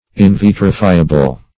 Search Result for " invitrifiable" : The Collaborative International Dictionary of English v.0.48: Invitrifiable \In*vit"ri*fi`a*ble\, a. Not admitting of being vitrified, or converted into glass.
invitrifiable.mp3